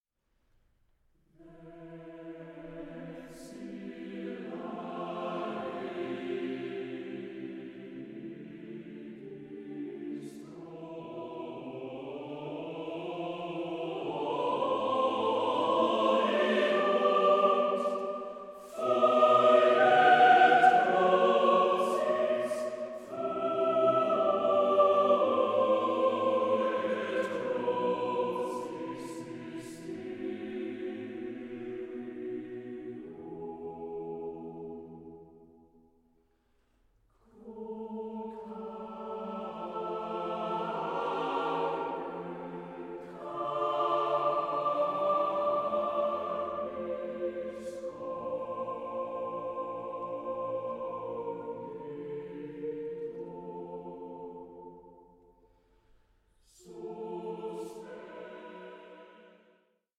Hymnus
TWO MASTERS OF AUSTRIAN CHURCH MUSIC COMBINED